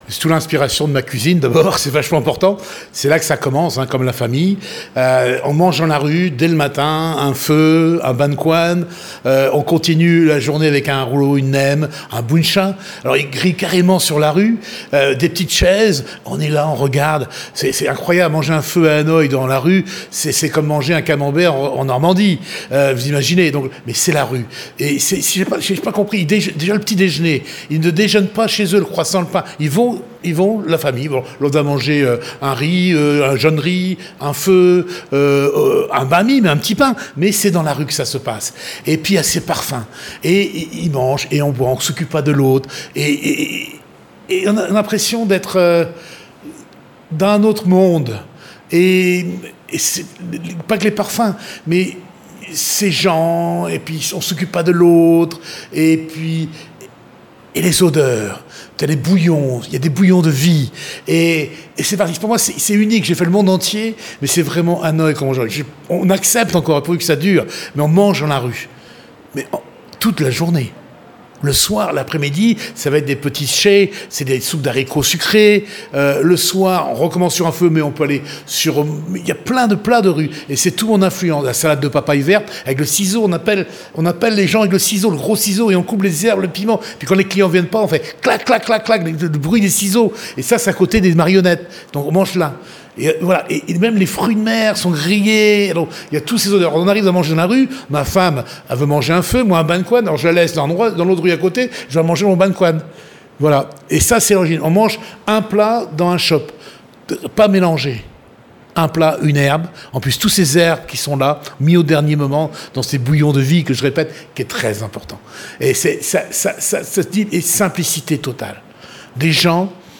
Il est tellement gourmand et passionné qu’il en mangerait ses mots.